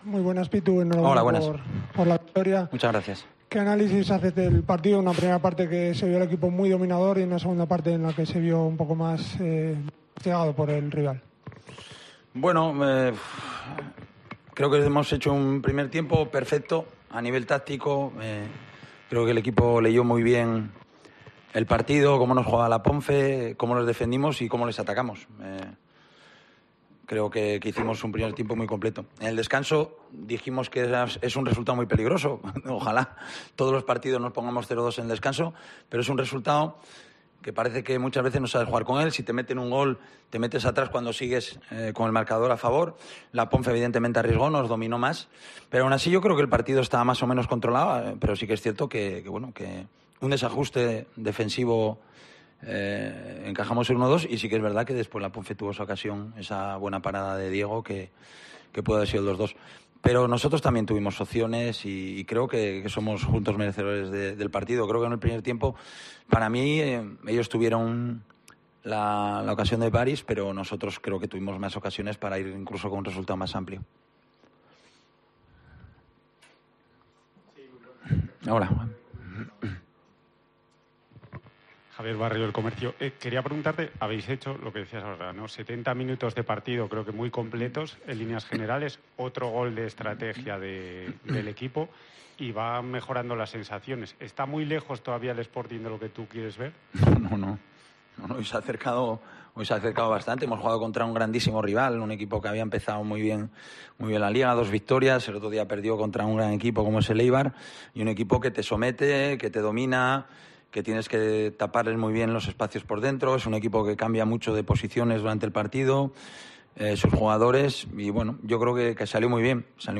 Rueda de prensa de Abelardo (post Ponferradina)